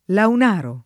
[ laun # ro ]